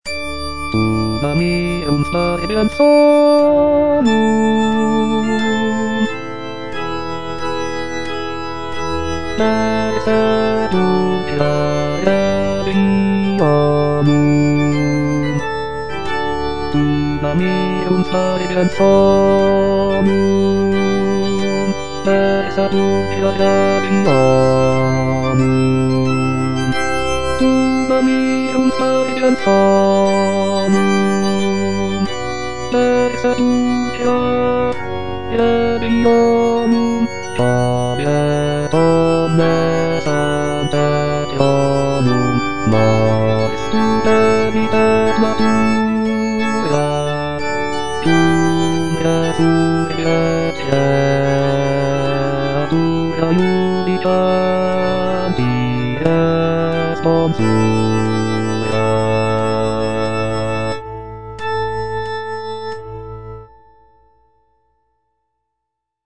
Bass (Voice with metronome) Ads stop
is a sacred choral work rooted in his Christian faith.